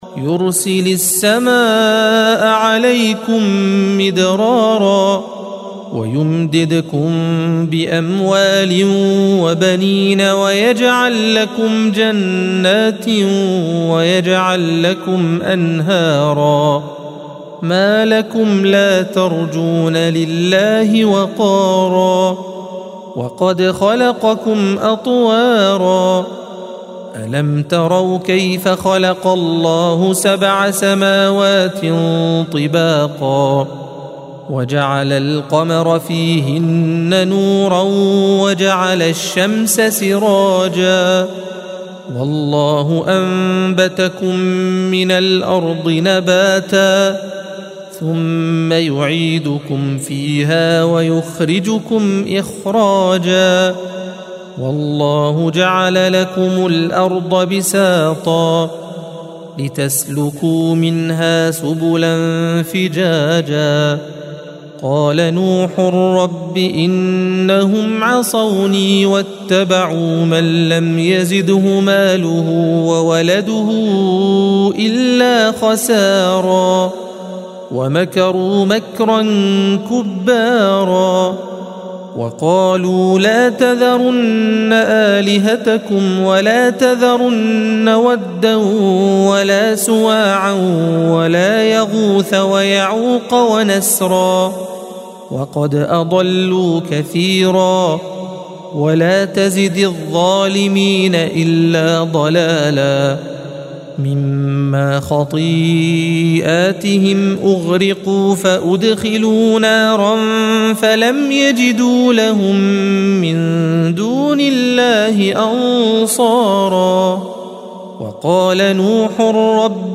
الصفحة 571 - القارئ